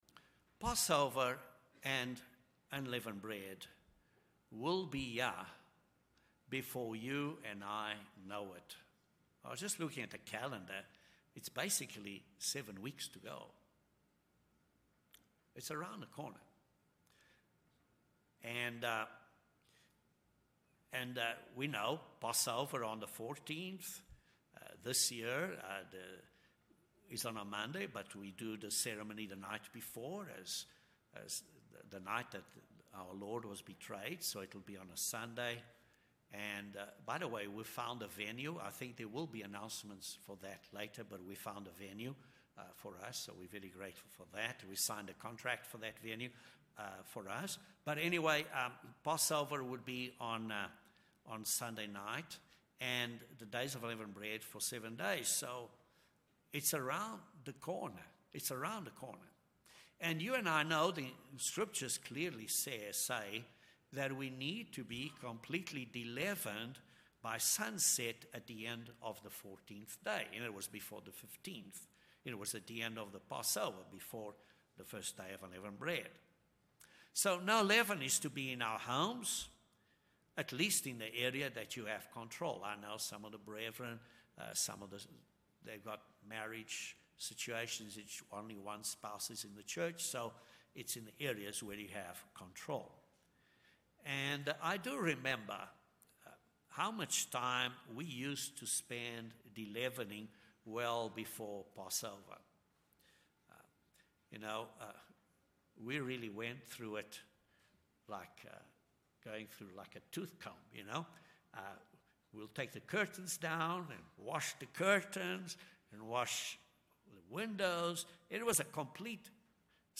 In addition to physically deleaven ourselves before Passover and Unleavened Bread, we need to most importantly spiritually “deleaven” ourselves by examining ourselves carefully before the Passover. Using four points from the original Passover of Exodus 12, this sermon expounds four principles to spiritually examine ourselves.